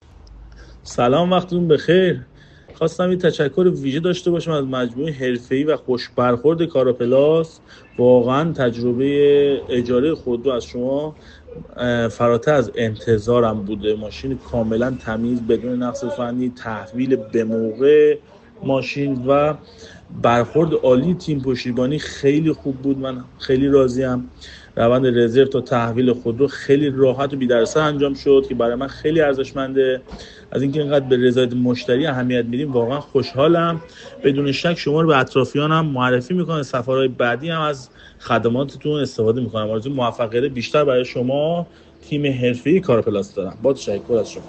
تجربه اجاره خودرو در دبی را از زبان مشتریان کاراپلاس بشنوید